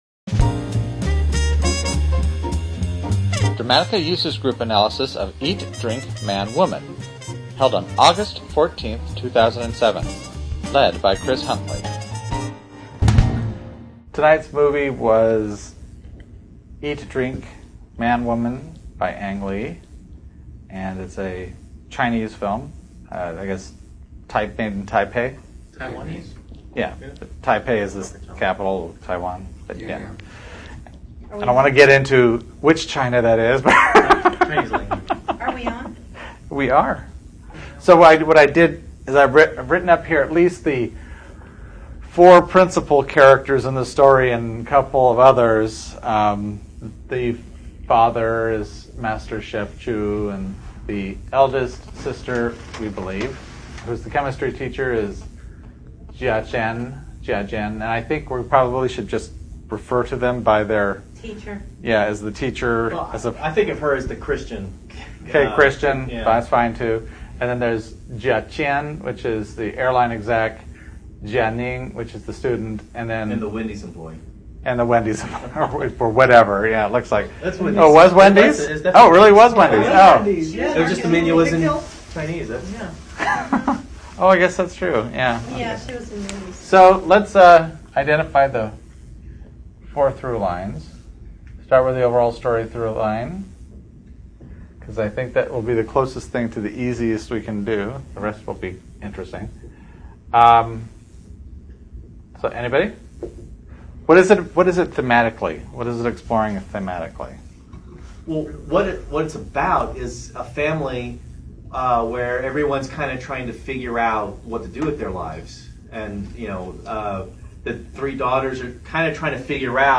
Delightful recordings of Dramatica Users attempting to better understand what makes great stories so great. Each podcast focuses on a popular or critically-acclaimed film. By breaking down story into fine detail, the group gains a better appreciation of the theory and how better to apply the concepts into their own work.